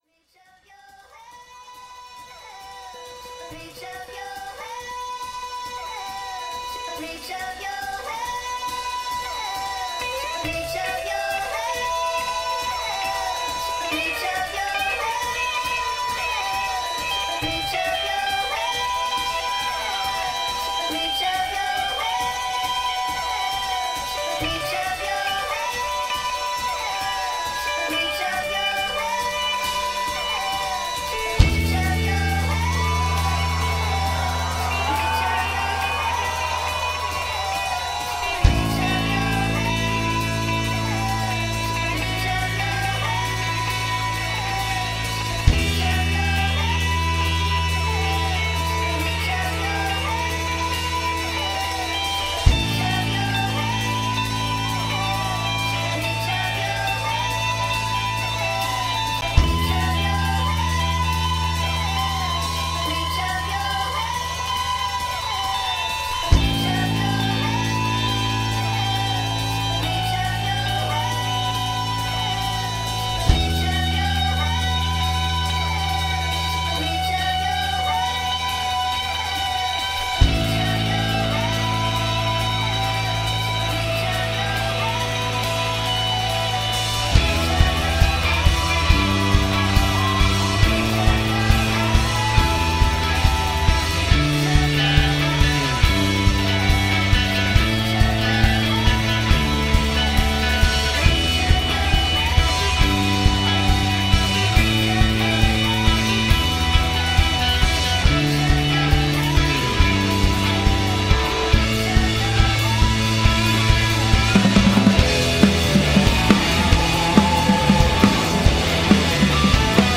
in concert at Route du Rock 2017, St. Malo, France
referred to as a cracked Pop Oddity